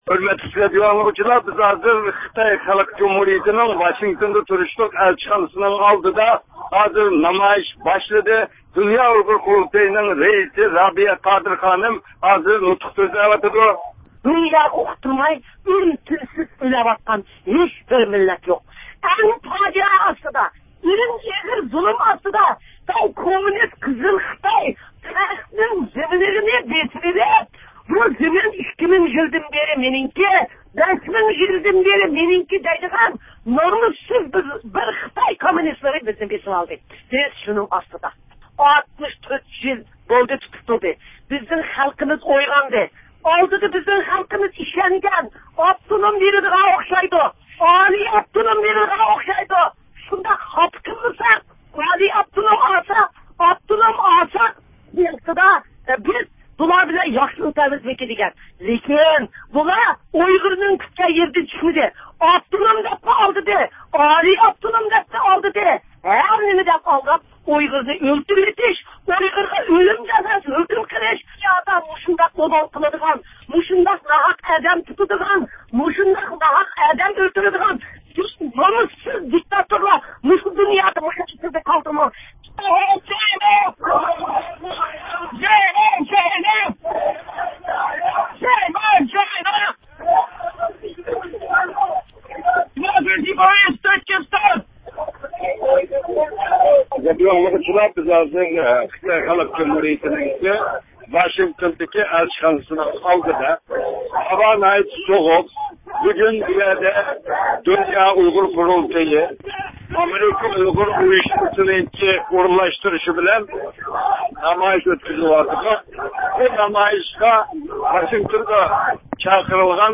نامايىش دۇنيا ئۇيغۇر قۇرۇلتىيىنىڭ رەئىسى رابىيە قادىر خانىمنىڭ نۇتقى بىلەن باشلاندى.